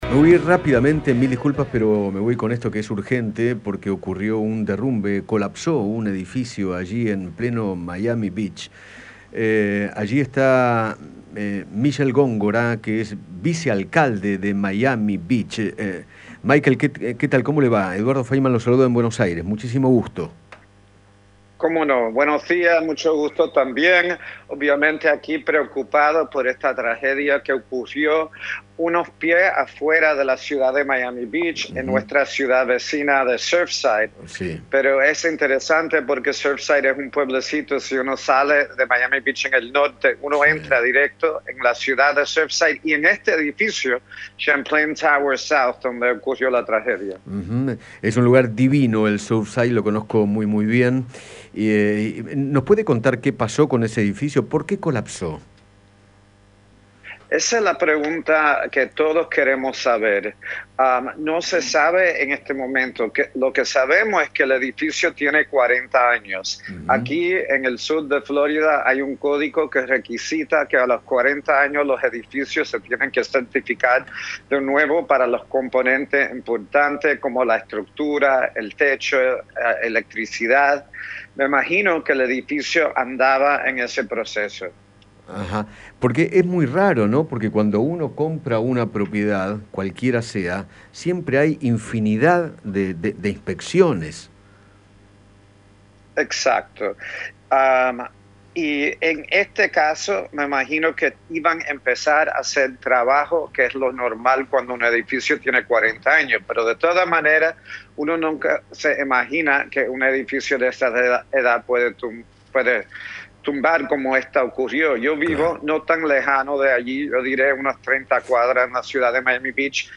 Michael Góngora, vicealcalde de Miami, habló con Eduardo Feinmann sobre el derrumbe parcial de un edificio de 12 pisos cerca de aquella ciudad.